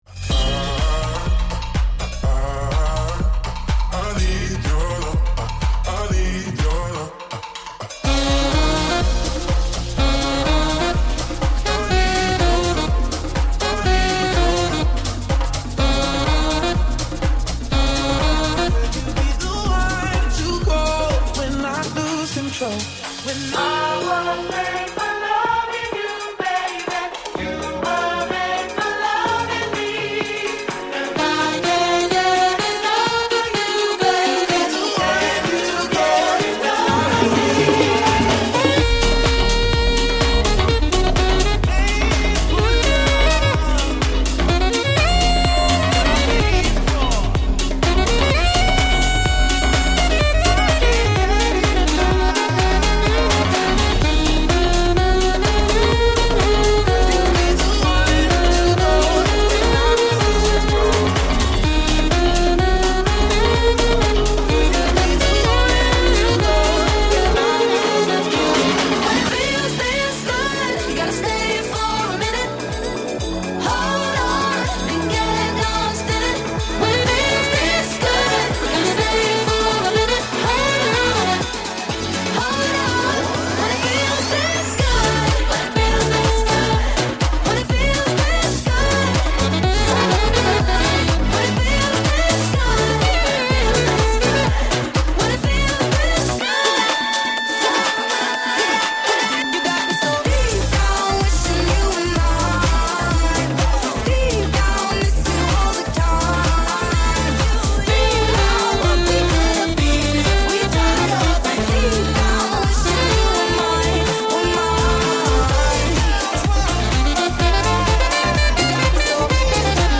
• Features talented female DJ/Vocalist
• Highly energetic and engaging